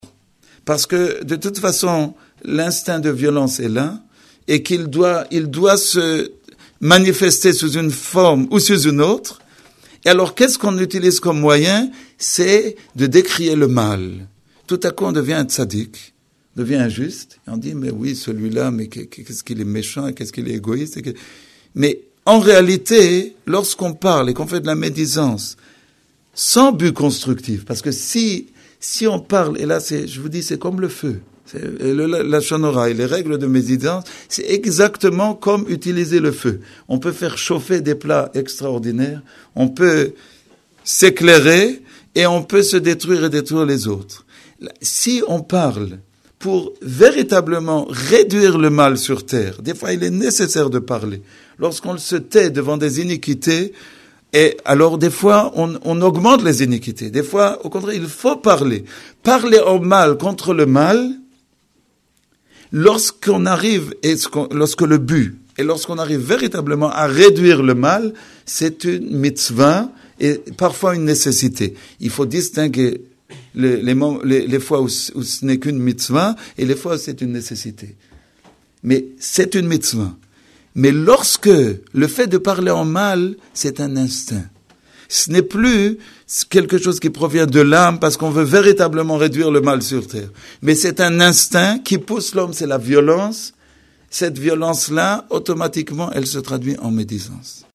grand exposé